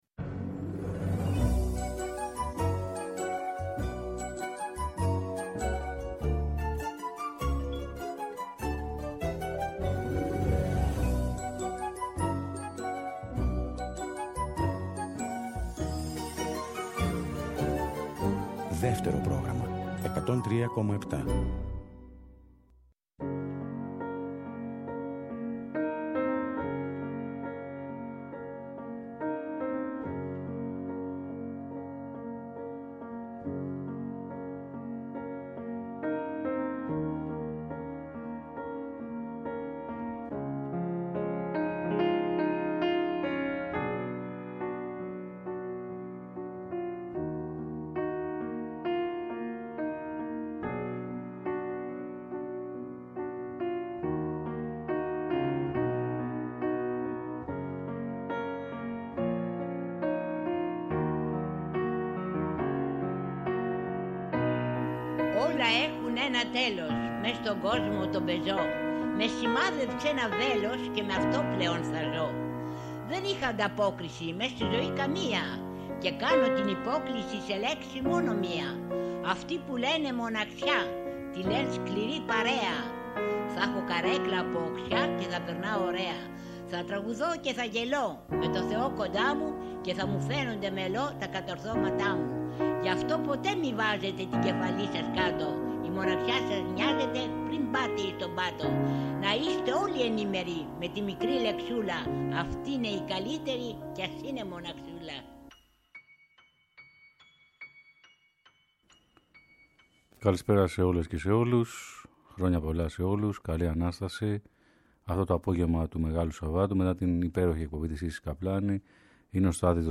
ζωντανά από το στούντιο του Δεύτερου. Η Χαρμολύπη είναι στοιχείο του ελληνικού τραγουδιού.
Τραγούδια πένθιμα και λυτρωτικά αυτό το Σάββατο 15 Απριλίου στην Αντέλμα στις 5 το απόγευμα ακριβώς!